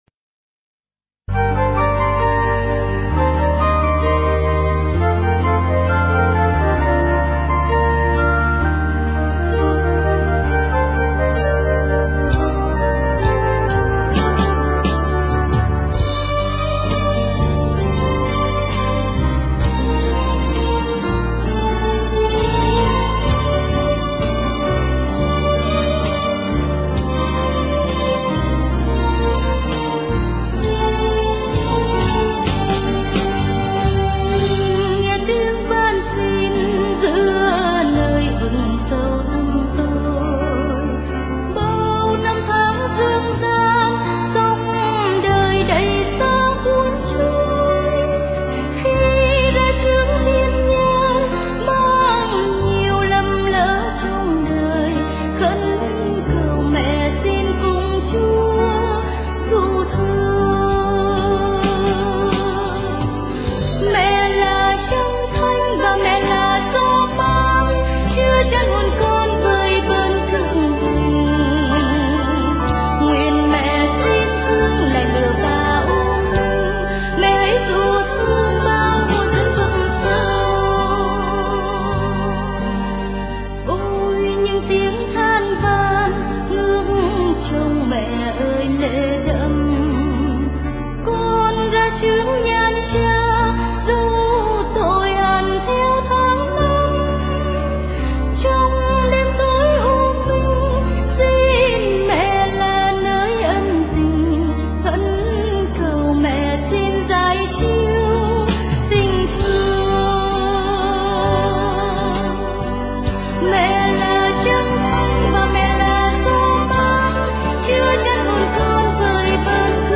* Thể loại: Cầu hồn